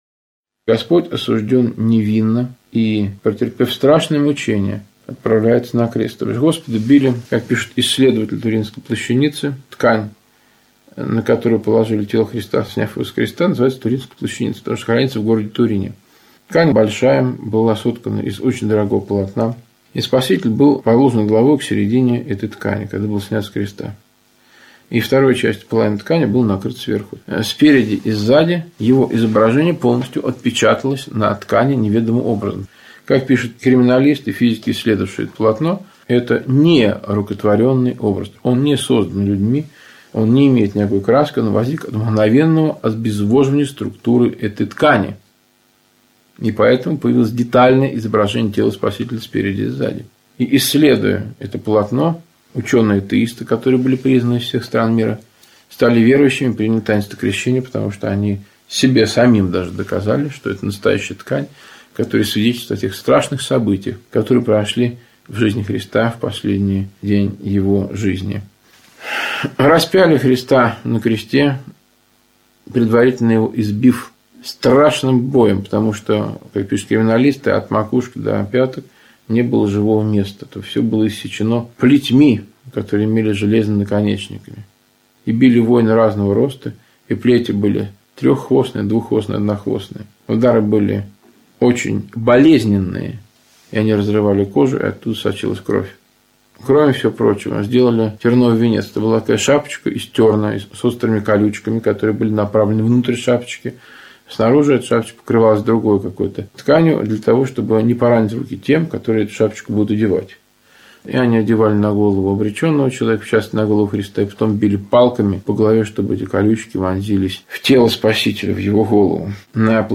Аудиокнига: Беседы перед Крещением